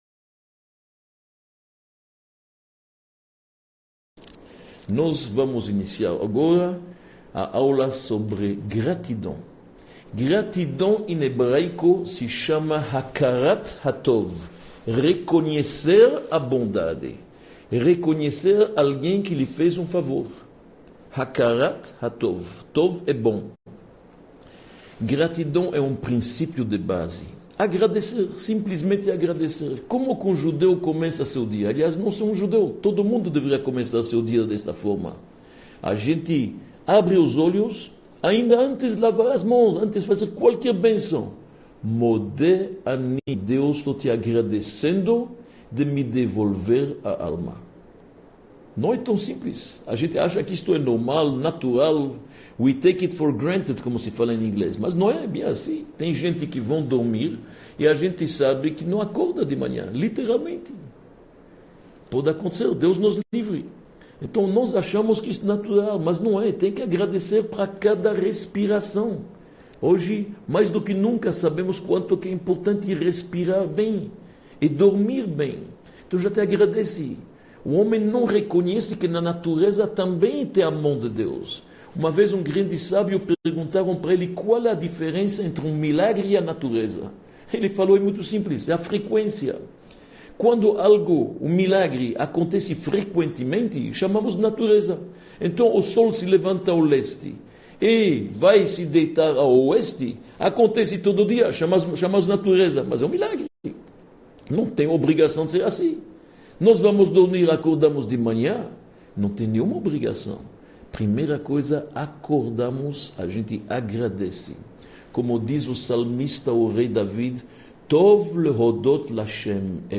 05 – A nobre arte da gratidão | Comportamento e Atitudes – Aula 05 | Manual Judaico